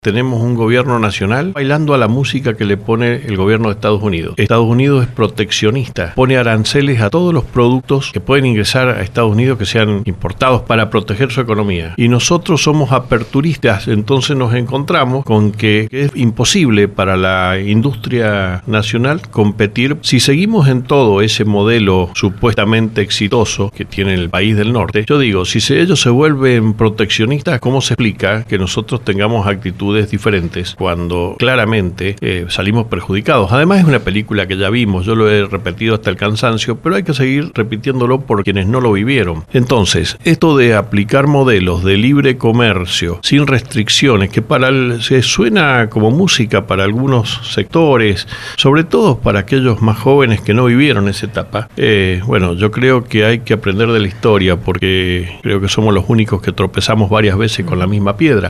La falta de acompañamiento del Estado nacional, su economía, la autonomía municipal y la modernización del Estado fueron algunos de los temas sobre los que habló Omar Félix -intendente de San Rafael- en LV18, apenas unas horas más tarde de su discurso de apertura de sesiones ordinarias del Concejo Deliberante.